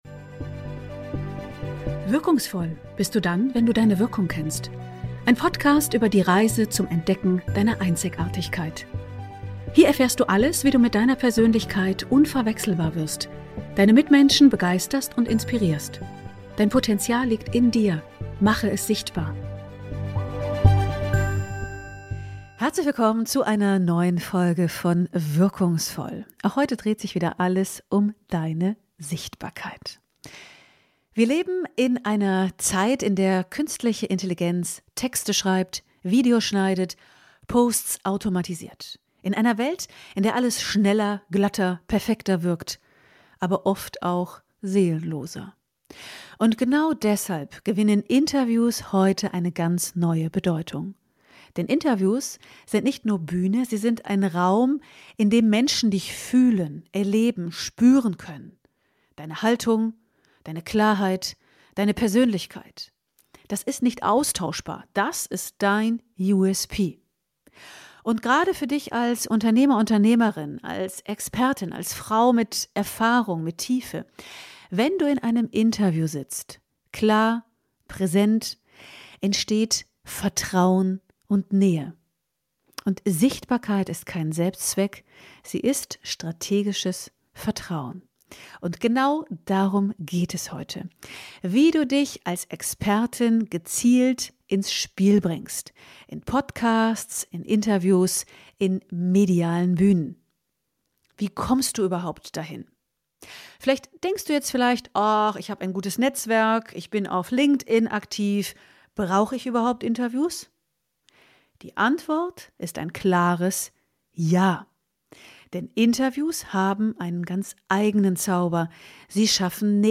Download - #15 Atme dich zum Erfolg: Life-Hacks für mehr Leistung und weniger Stress! Im Interview